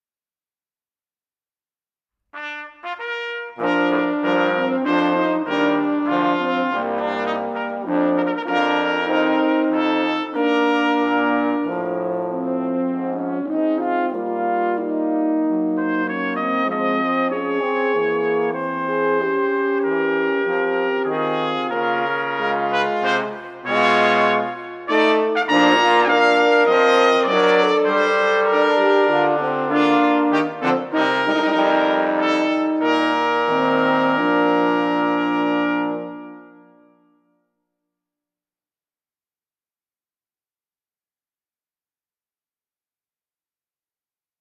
ソルフェージュ 聴音: コンサートホール・ヴァージョン(金管合奏)
コンサートホール・ヴァージョン(金管合奏)